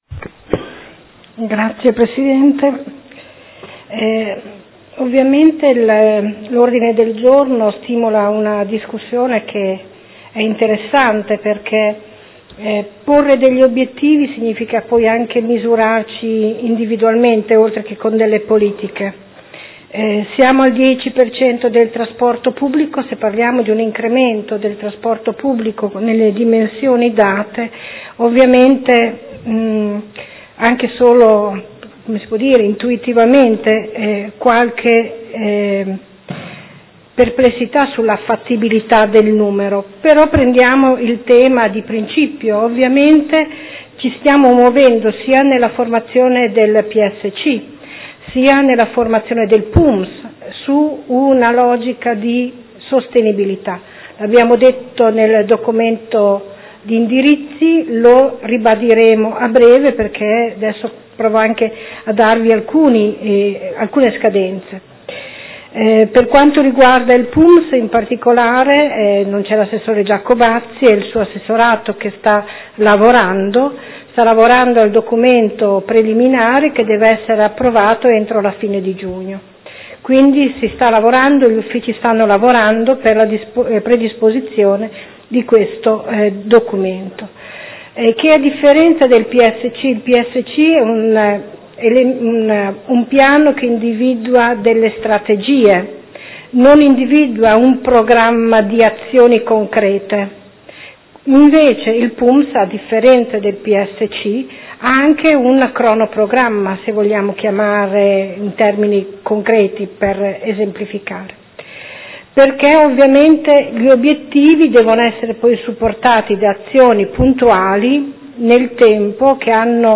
Seduta del 14/04/2016 Dibattito. Ordine del Giorno presentato dal Consigliere Montanini del Gruppo Consiliare CambiAMOdena avente per oggetto: Criteri di pianificazione urbanistica